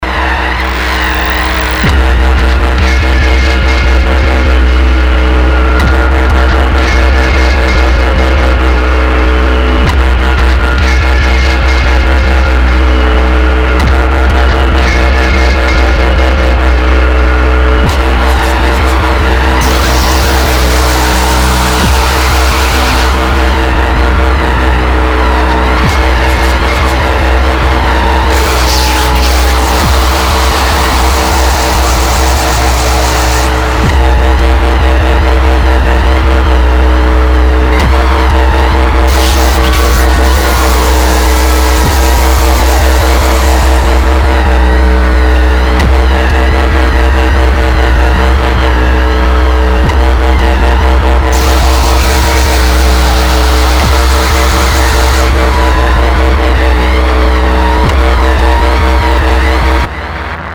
• Genre: Power Electronics / Death Industrial